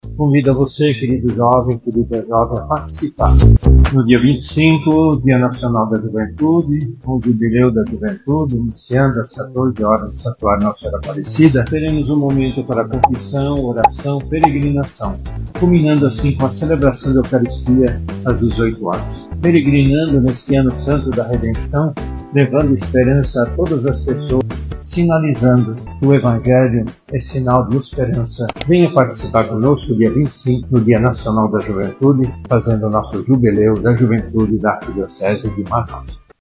Em Manaus, o encontro está marcado para o dia 25 de outubro, no Santuário Nossa Senhora Aparecida, com início às 14h e previsão de término às 20h. Será uma tarde de muita fé, música e partilha, conforme destaca Dom Leonardo  Steiner, Arcebispo Metropolitano de Manaus.